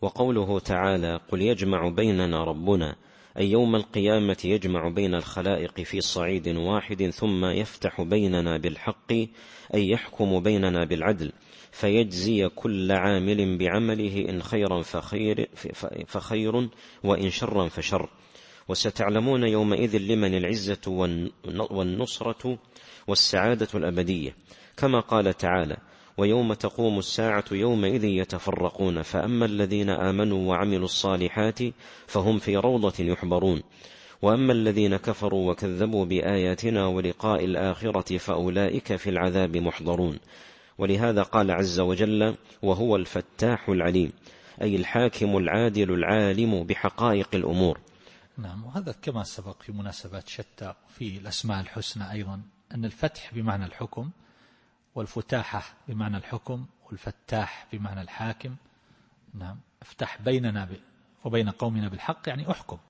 التفسير الصوتي [سبأ / 26]